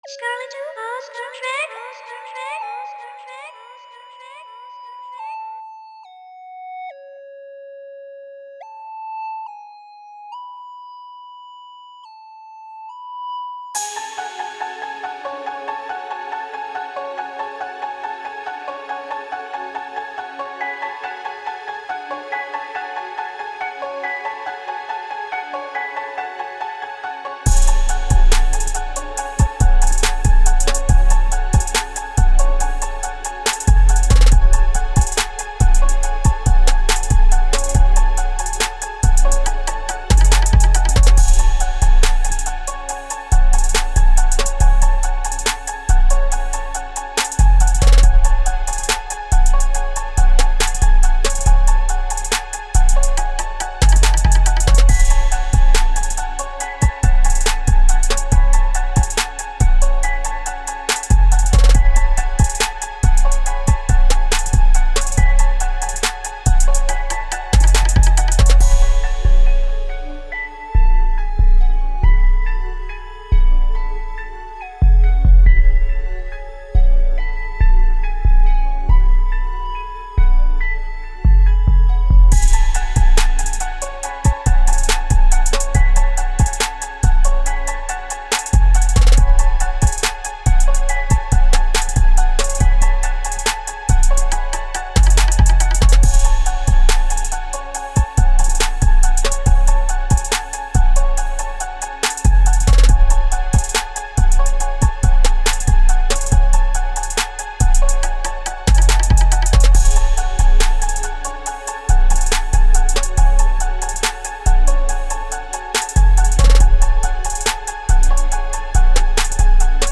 Instrumental Mixtape